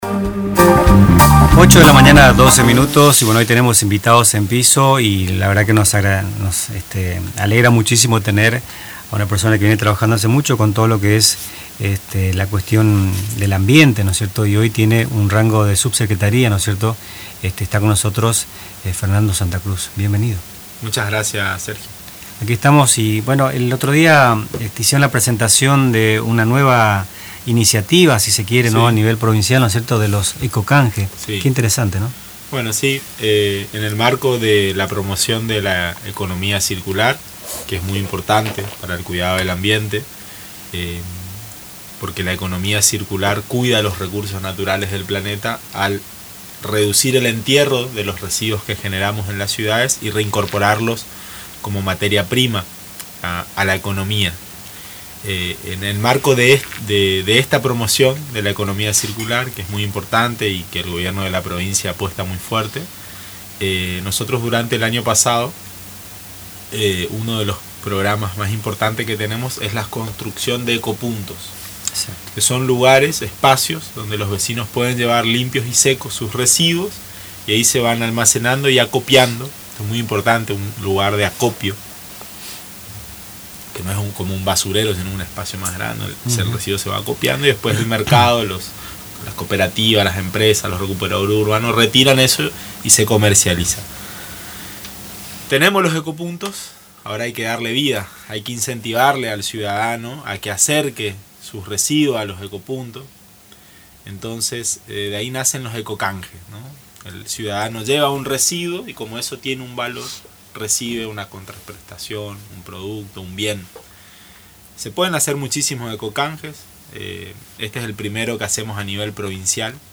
En una reciente entrevista en Radio Tupa Mbae, el Subsecretario de Economía Circular de la provincia de Misiones, Fernando Santa Cruz, compartió detalles sobre una nueva iniciativa destinada a promover la economía circular y el cuidado del medio ambiente. El programa, denominado "Dulce Ecocanje", busca incentivar a los ciudadanos a participar activamente en la gestión de residuos y en la reutilización de materiales.
Durante la entrevista en los estudios de La Creíble FM 105.9, Santa Cruz explicó que el programa se enmarca en la promoción de la economía circular, la cual busca reducir el entierro de residuos y reincorporarlos como materia prima en la economía.